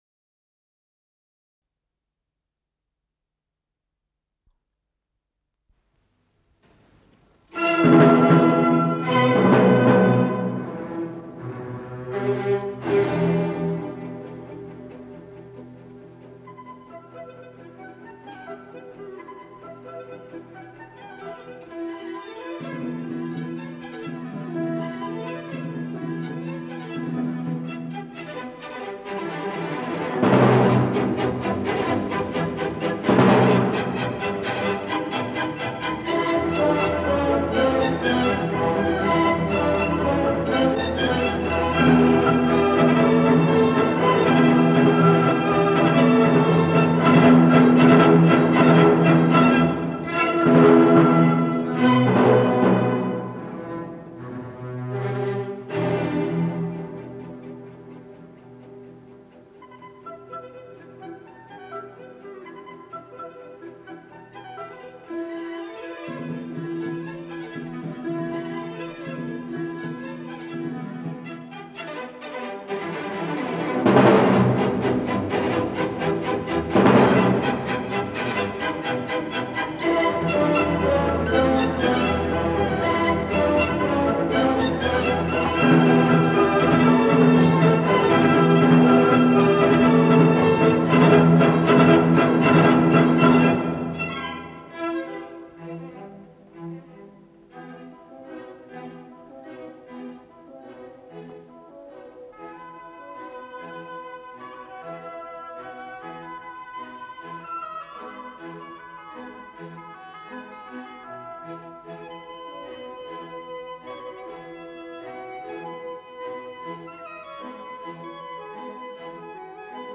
с профессиональной магнитной ленты
Скерцо мольто виваче
ИсполнителиОркестр Чешской филармонии
Дирижёр - Вацлав Нейман